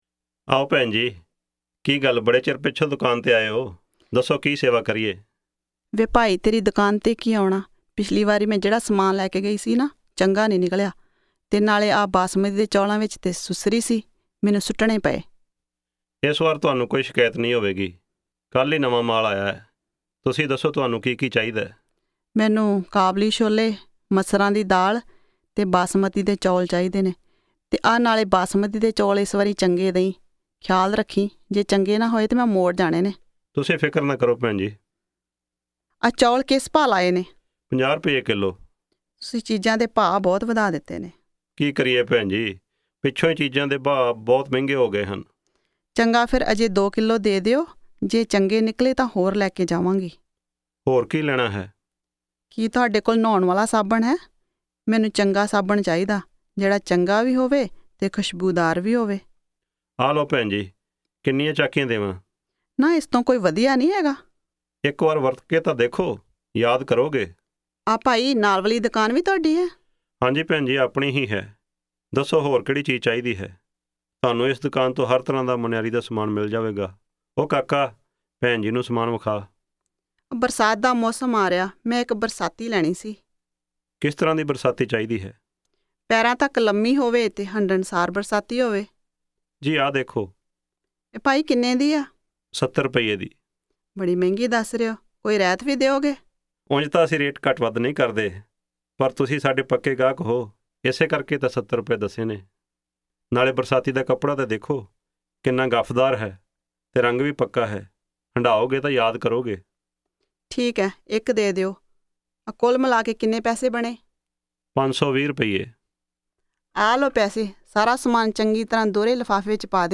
Punjabi Conversation 7 Listen
Listen to the customer and the shopkeeper.